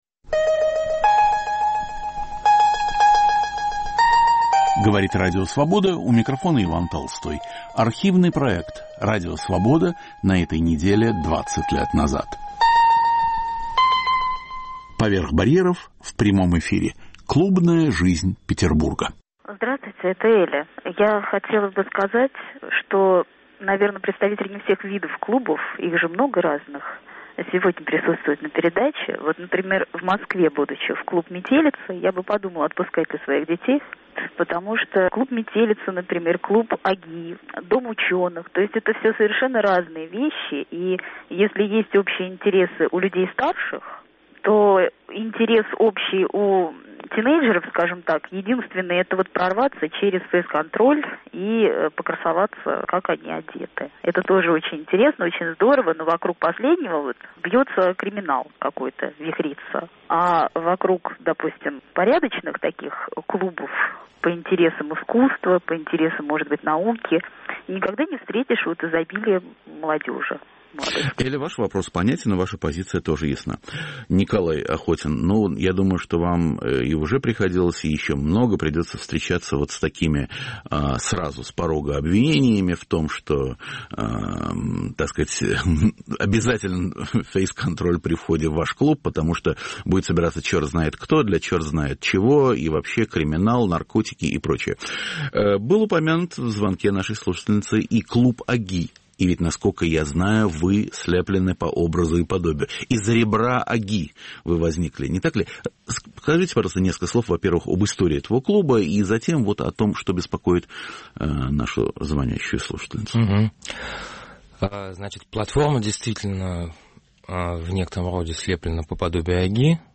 "Поверх барьеров" в прямом эфире. Клубная жизнь Петербурга
Автор и ведущий Иван Толстой.